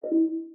buttonClick.ogg